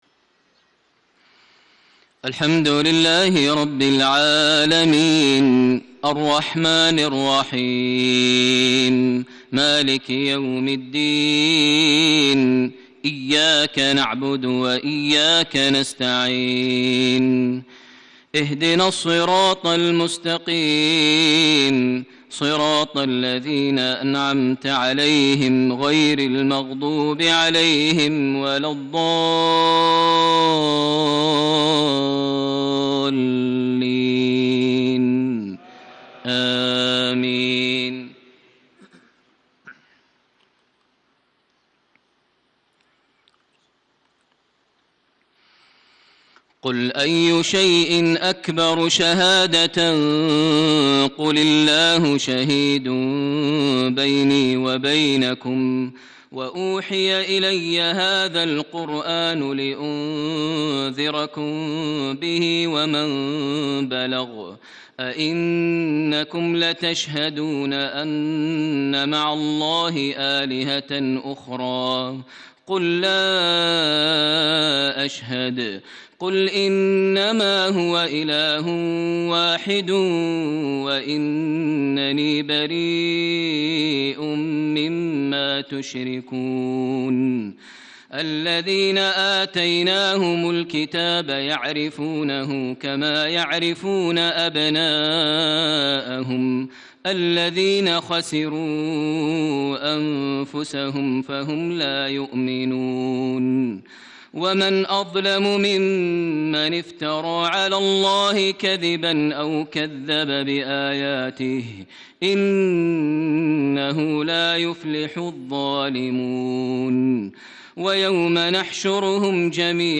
عشاء الأربعاء 2-6-1435هـ تلاوة مميزة جداً من سورة الأنعام 19-32 > 1435 هـ > الفروض - تلاوات ماهر المعيقلي